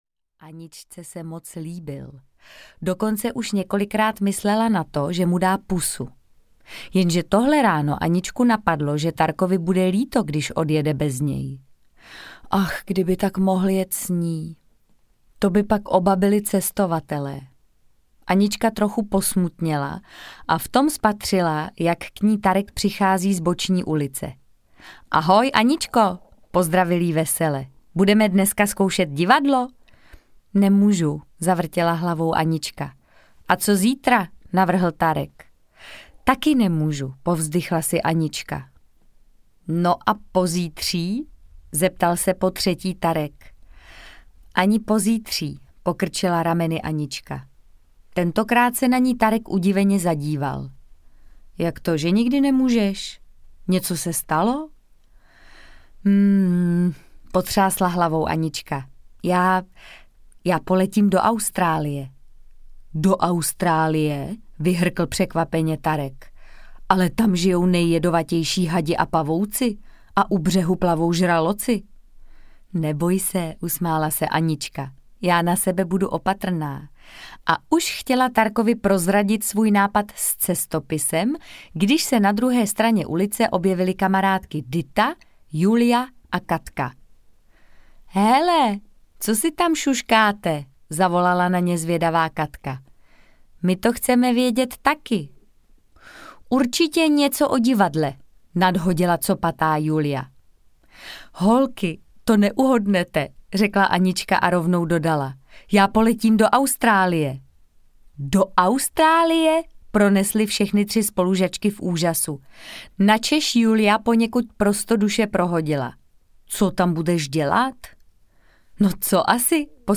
Ukázka z knihy
• InterpretMartha Issová